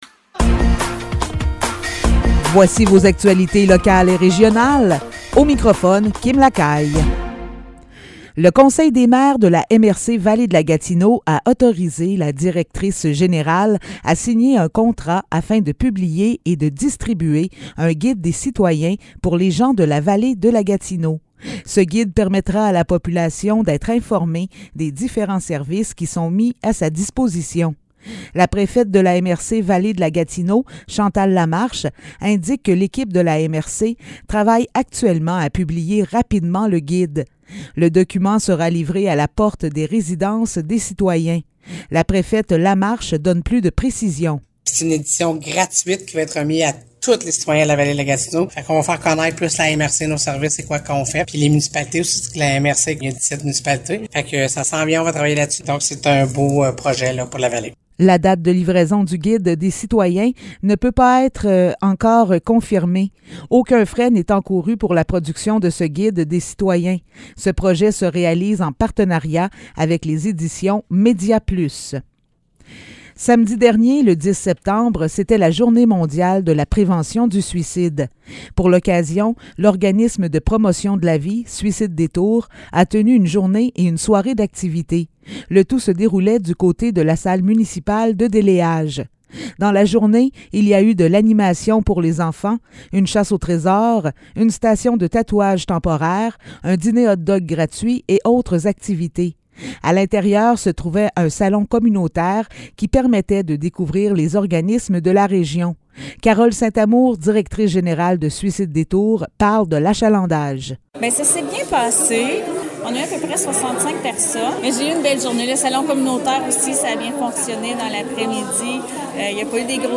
Nouvelles locales - 12 septembre 2022 - 15 h